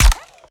Added more sound effects.
GUNAuto_RPU1 Fire_05_SFRMS_SCIWPNS.wav